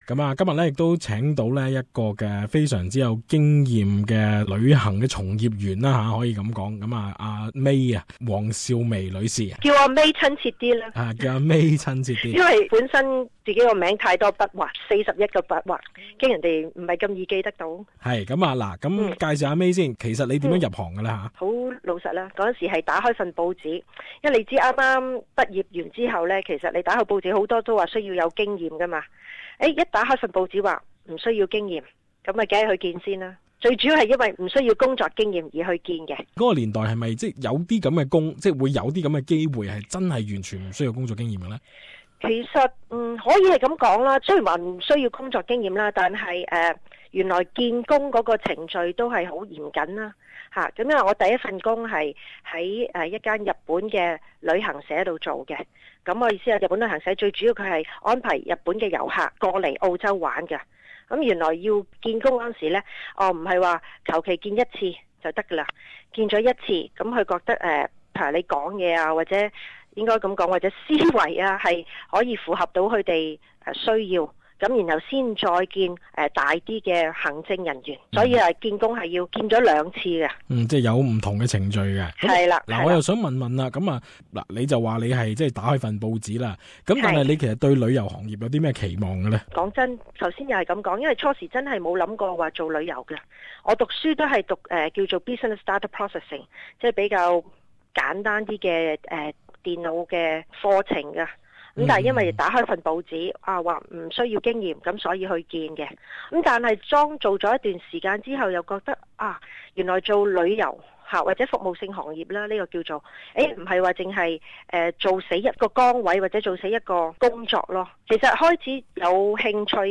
不如聽聽旅圖愉快內的訪談.